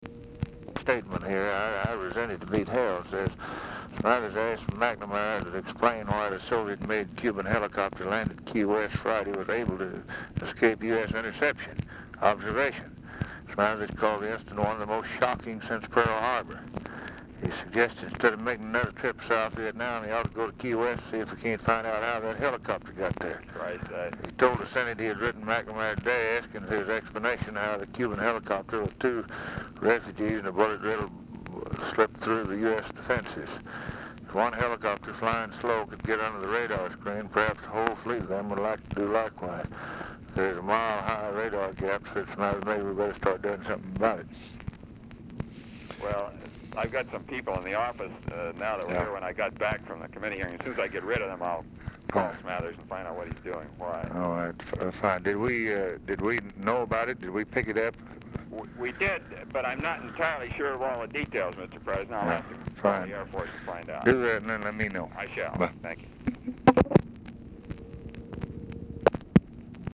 Telephone conversation # 2623, sound recording, LBJ and ROBERT MCNAMARA, 3/23/1964, 5:30PM | Discover LBJ
Format Dictation belt
Location Of Speaker 1 Oval Office or unknown location